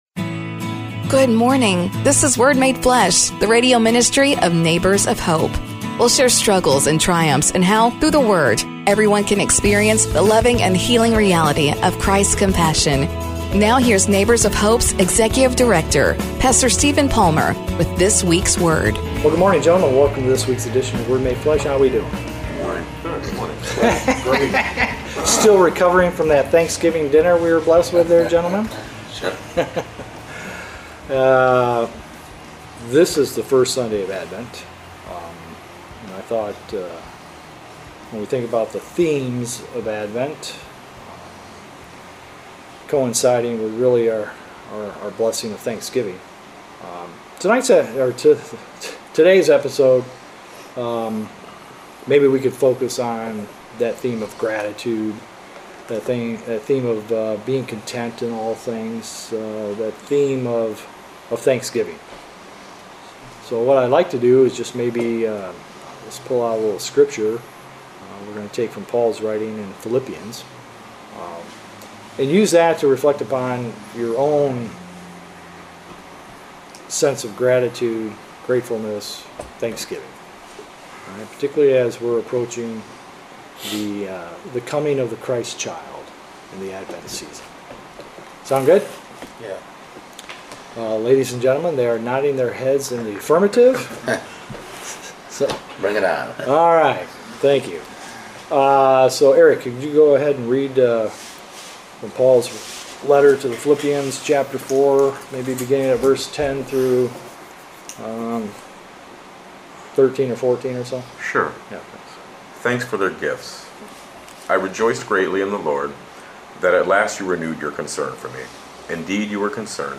Word Made Flesh is our effort to reach out to our neighbors through the radio. Every Sunday, listen to participants in our men’s programs share their encounters with the liberating power of God’s grace and mercy.